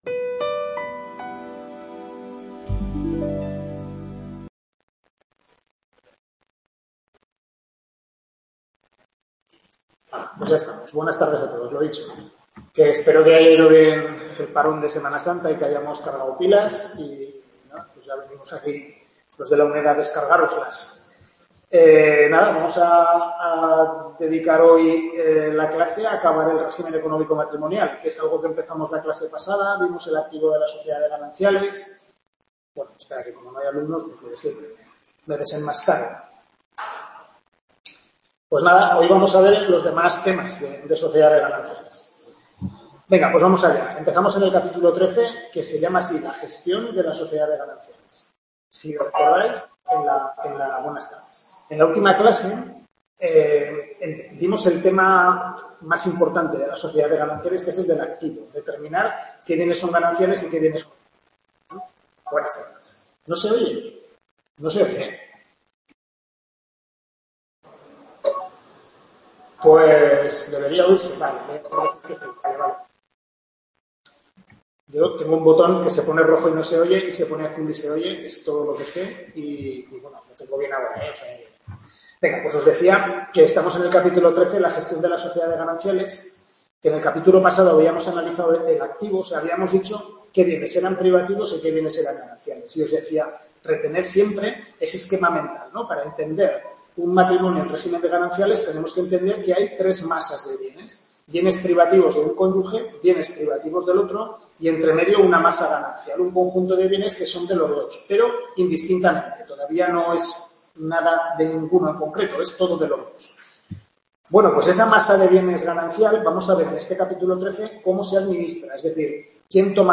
Tutoría de Civil I (Derecho de Familia), correspondiente a los capítulos 13 a 16 del Manual del Profesor Lasarte